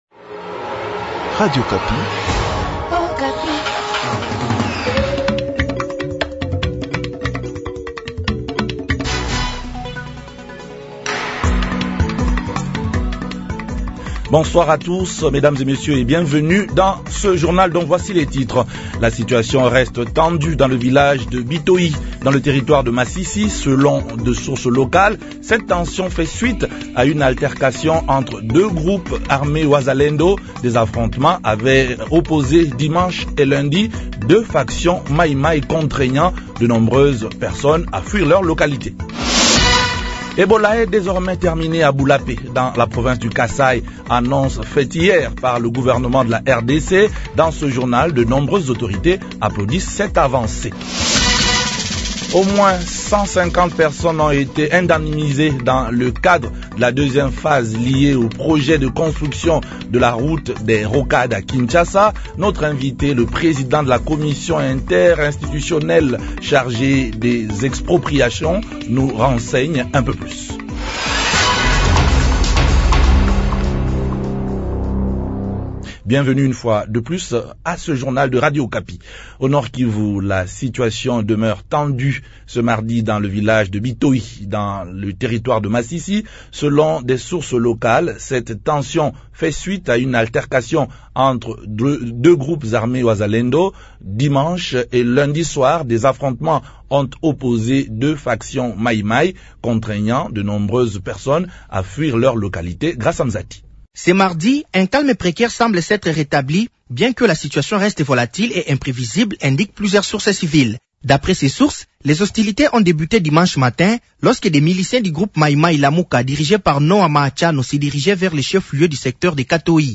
journal francais 15h
Au moins 150 personnes ont été indemnisées dans le cadre de la deuxième phase liée au projet de construction de la route des Rocades, à Kinshasa. Notre invite, le président de la commission interministérielle chargée des expropriations nous renseigne un peu plus.